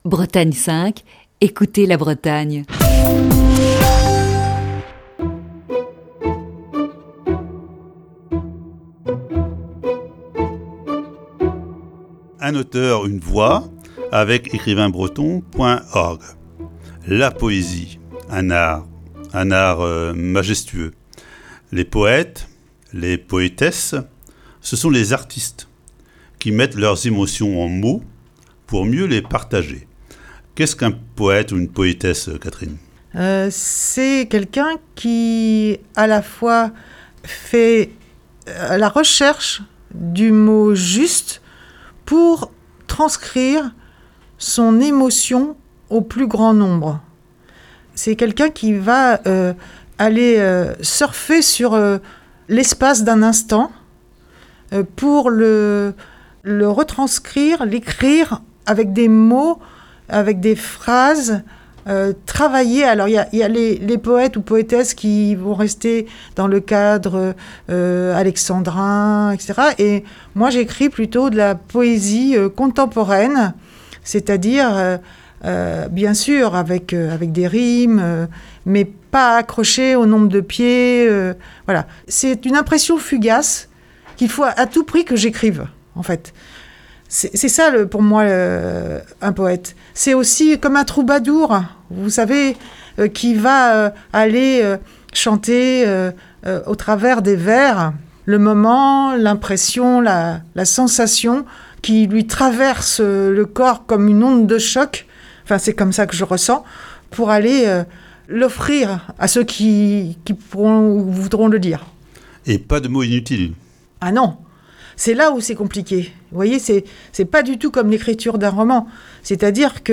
Ce jeudi, voici la quatrième partie de cette série d'entretiens.